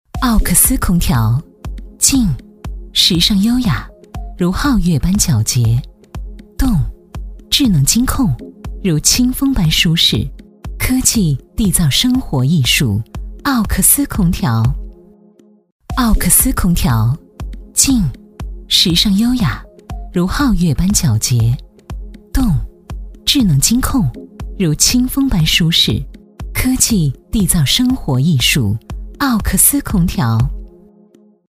职业配音员全职配音员电台风格
• 女S125 国语 女声 品牌广告—奥克斯空调 积极向上|时尚活力|神秘性感|亲切甜美